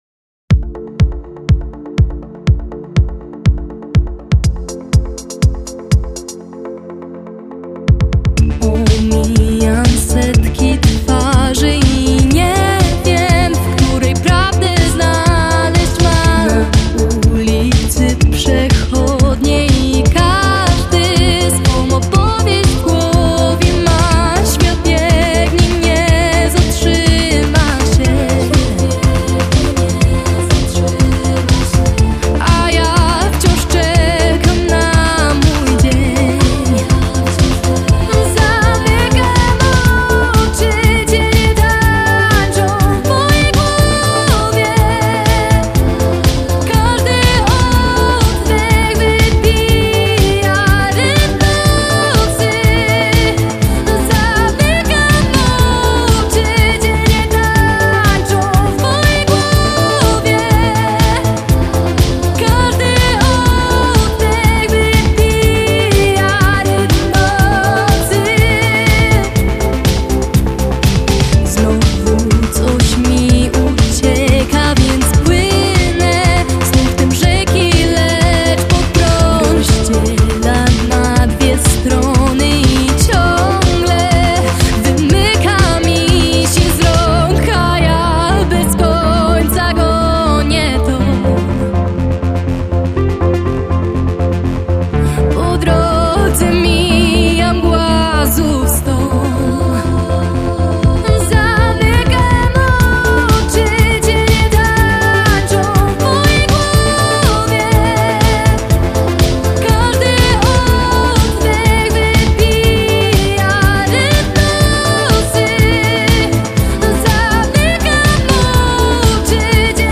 w mrocznej, niepokojącej wersji?